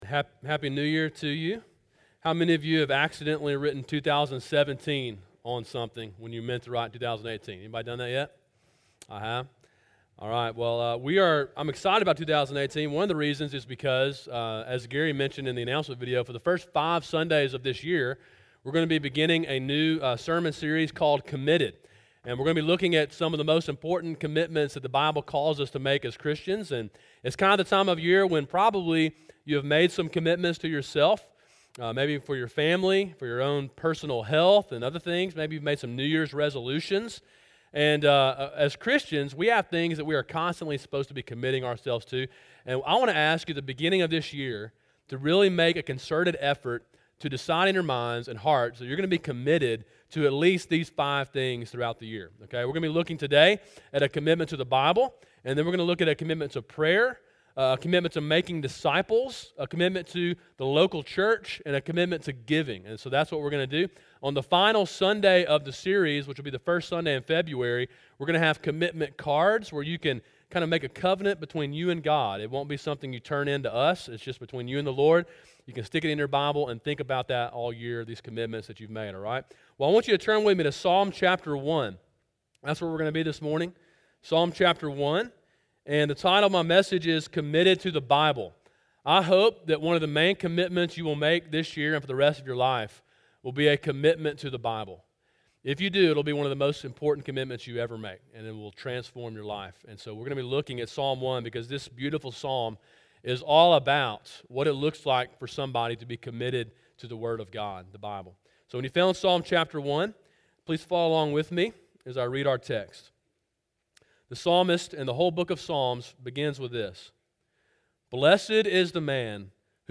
Sermon: “Committed to the Bible” (Psalm 1) – Calvary Baptist Church